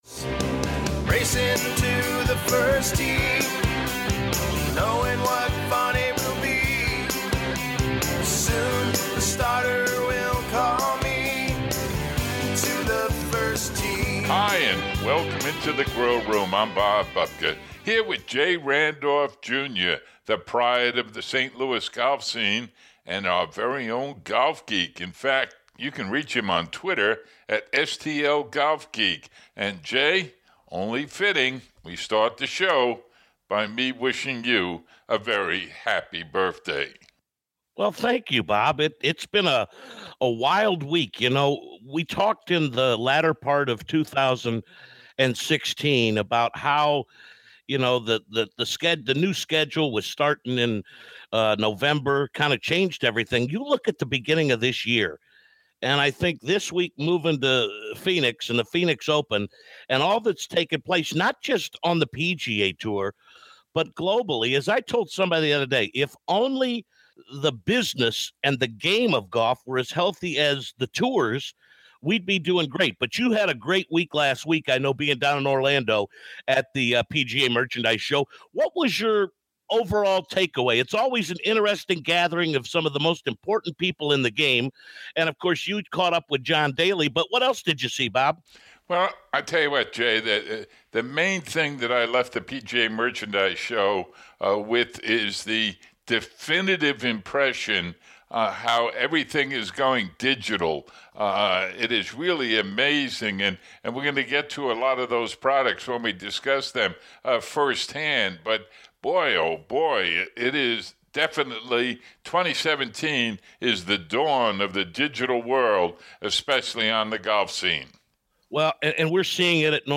The final segment is full of interviews: Imperial Golf, Vertical Groove Golf, Galvin Green, Arccos Golf and Voice Caddie